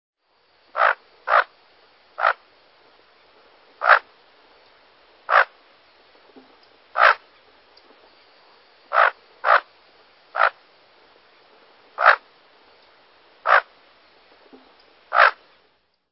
Bufflehead
Bucephala albeola
Bird Sound
Usually silent. Courtship display includes guttural chattering. Male may give squeal or growl in late winter or spring; females give a throaty cluck when seeking nests in summer.
Bufflehead.mp3